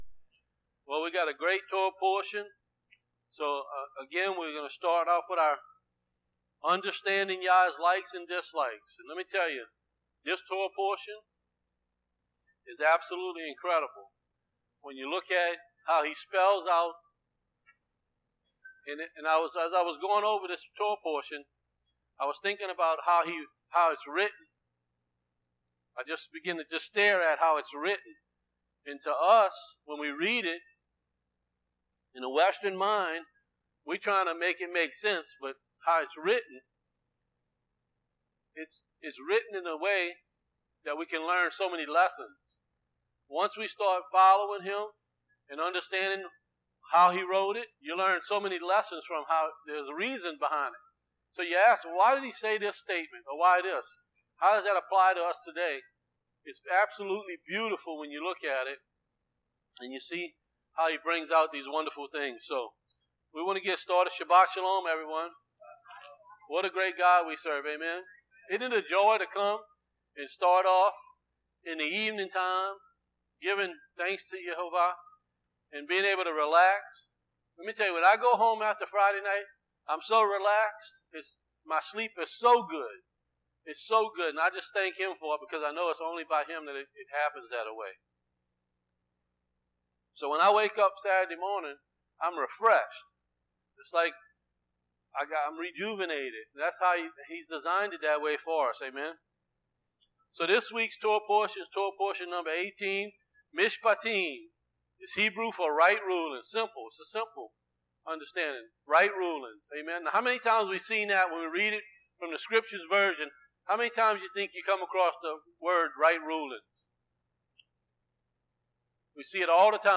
Torah Teachings -Mishpatim Part 1